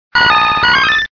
Fichier:Cri 0071 DP.ogg — Poképédia